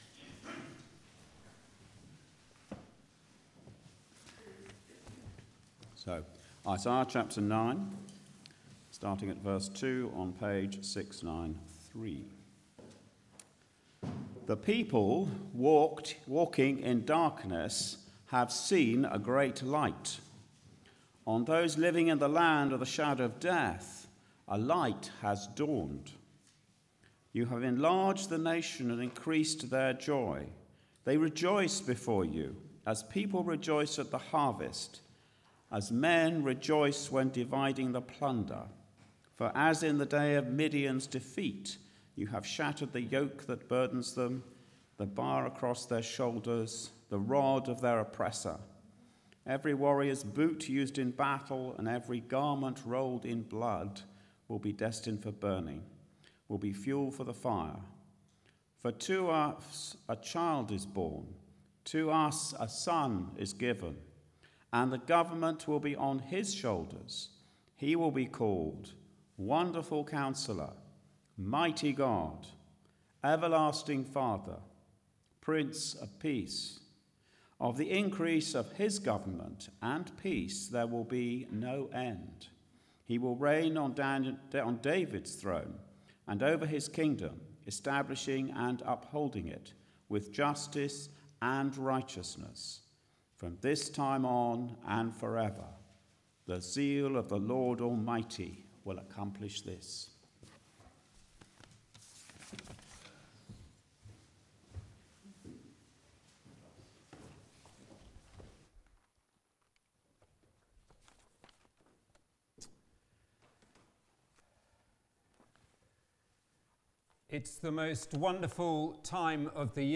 Media for Arborfield Morning Service on Sun 11th Dec 2022 10:00
Sermon